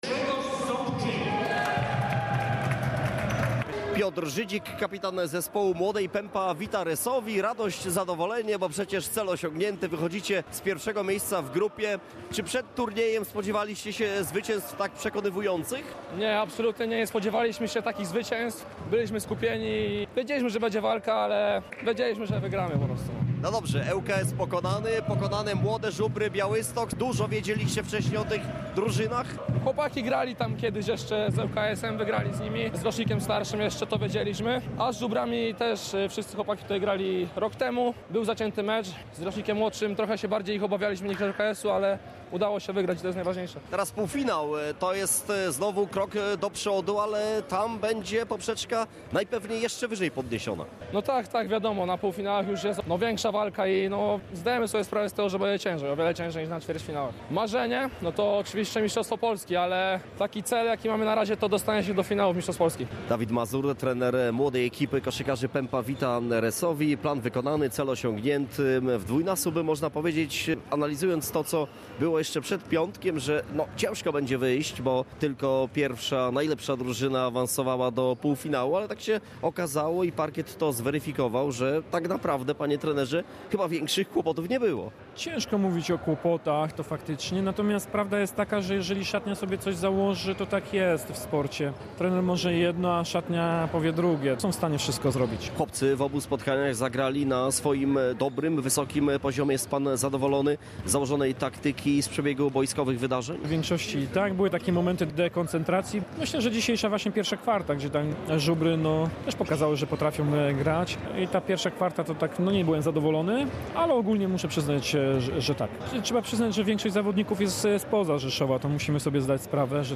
Pomeczowe rozmowy